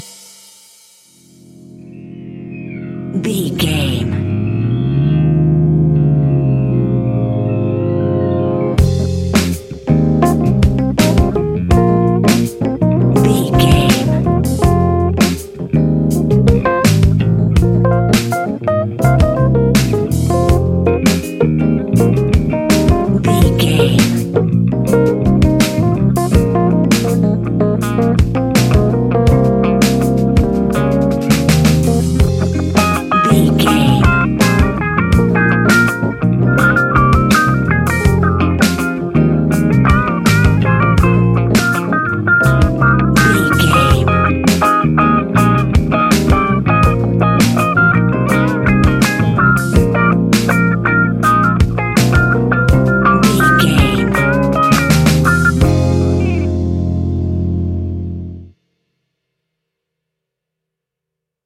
Fast paced
Uplifting
Ionian/Major
F♯
hip hop